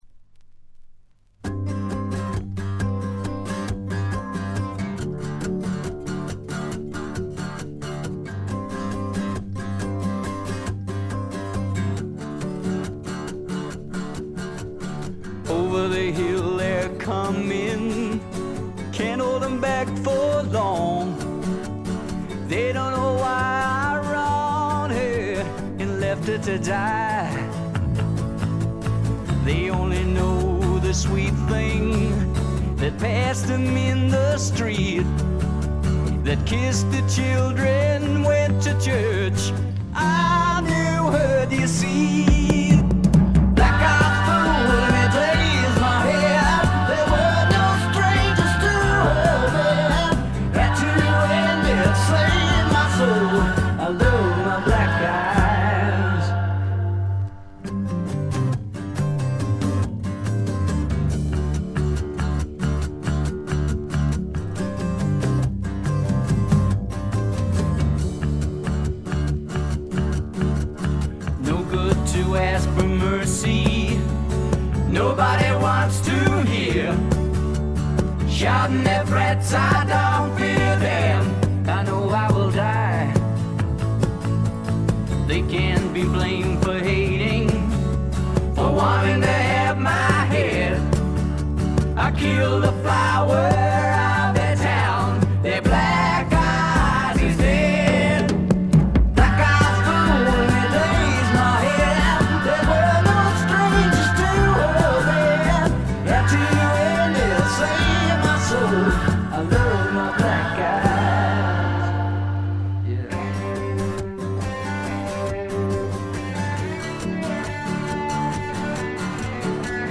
部分試聴ですがチリプチ少し。
試聴曲は現品からの取り込み音源です。